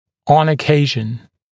[ɔn ə’keɪʒn][он э’кейжн]иногда, время от времени, в некоторых случаях